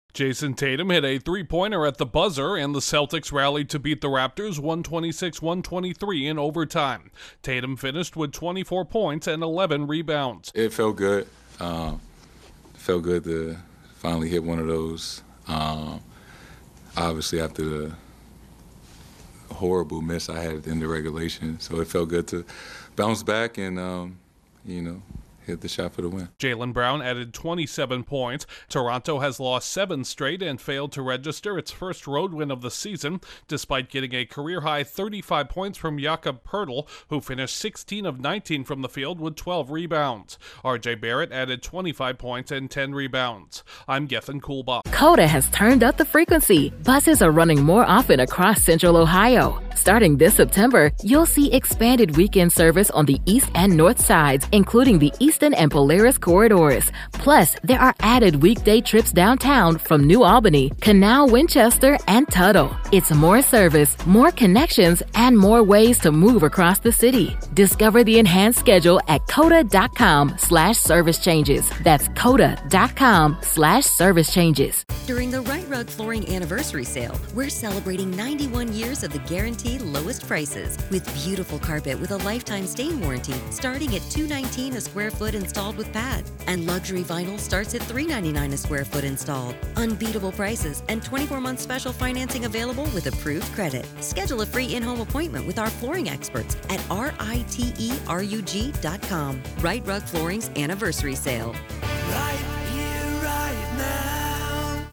Jayson Tatum’s second career game-winning shot helped the Celtics survive against the Raptors. Correspondent